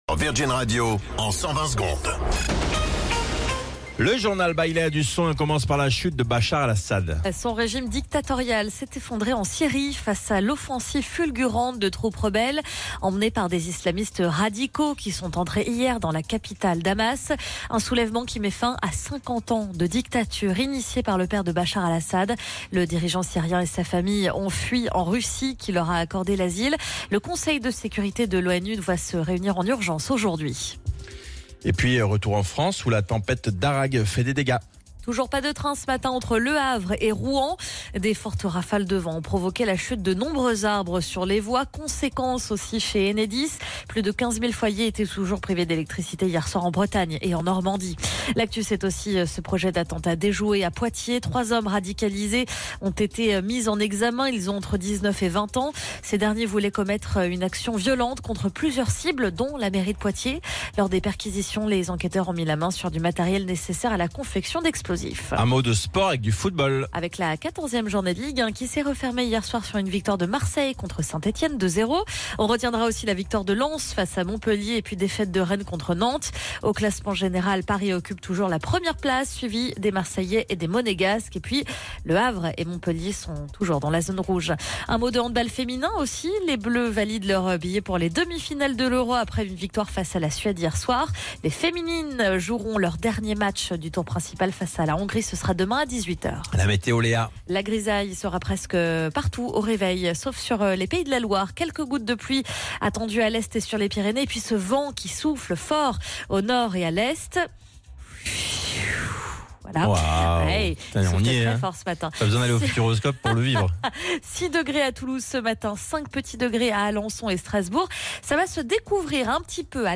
Flash Info National 09 Décembre 2024 Du 09/12/2024 à 07h10 .